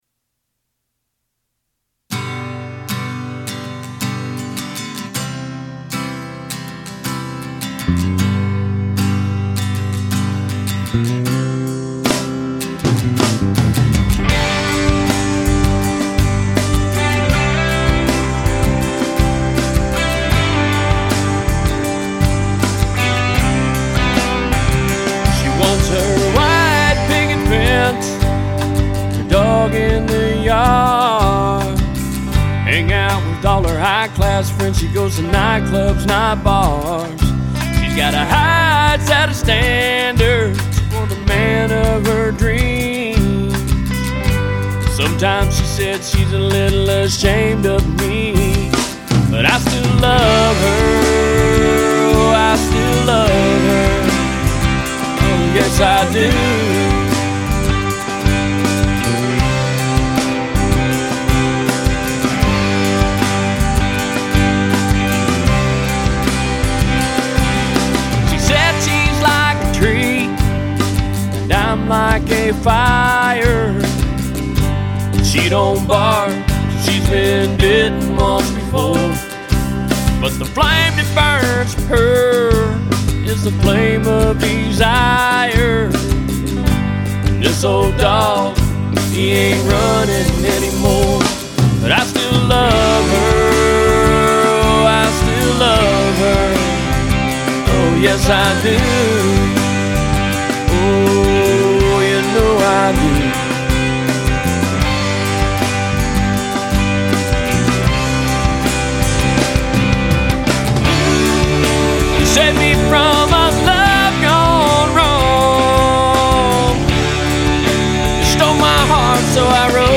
Country tune
Tune I just finished with a band.